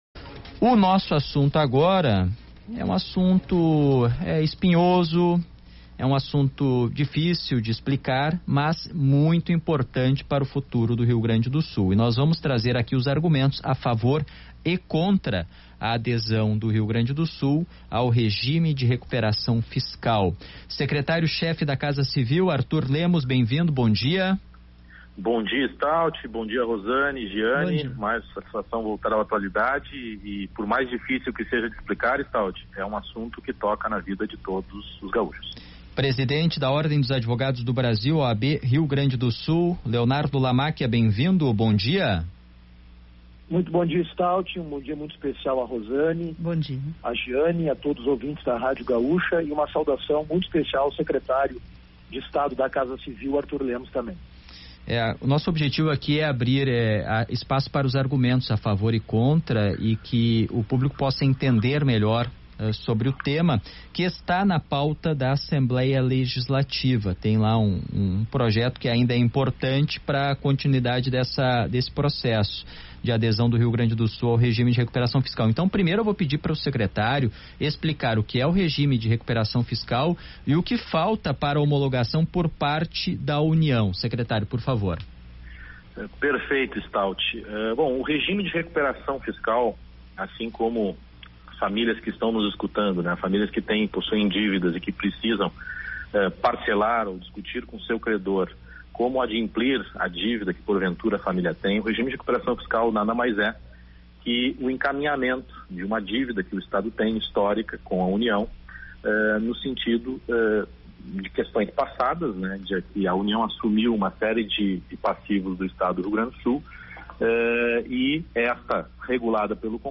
Entrevista com o Secret�rio-Chefe da Casa Civil